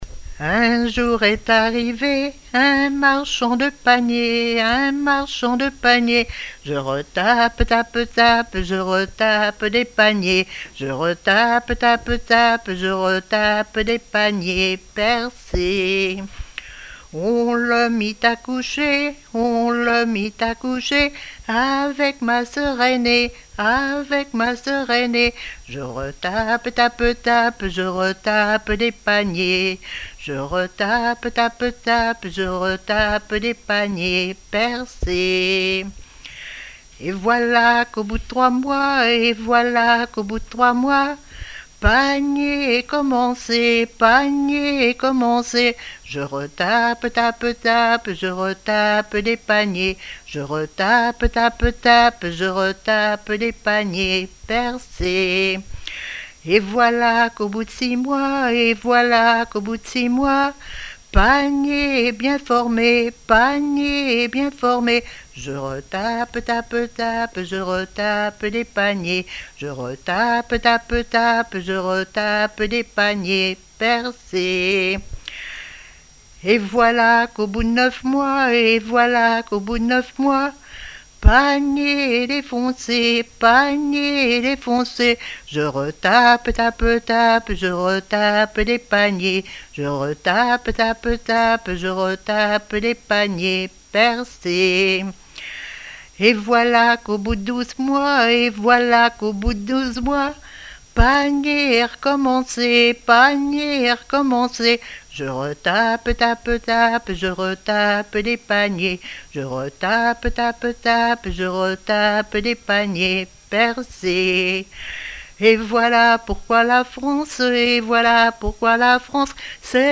Genre laisse
répertoire de chansons et témoignages
Pièce musicale inédite